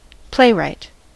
playwright: Wikimedia Commons US English Pronunciations
En-us-playwright.WAV